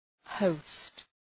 Προφορά
{həʋst}